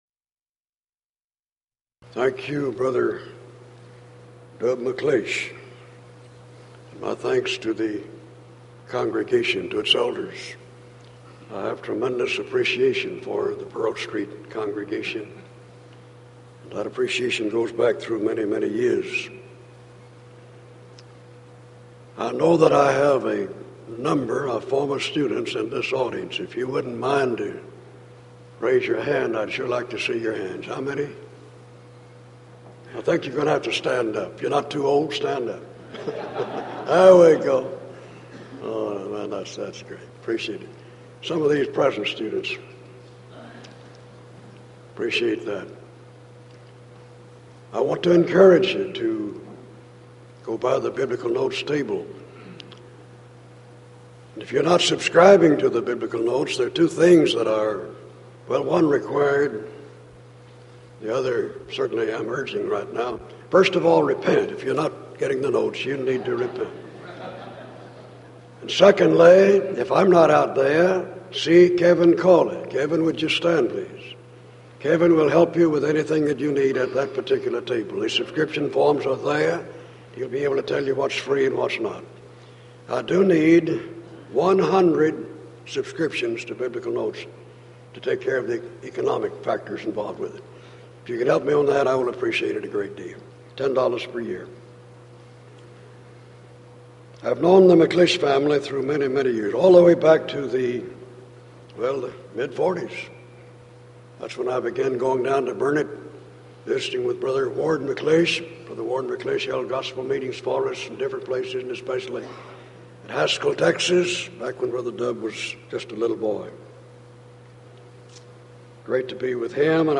Event: 1993 Denton Lectures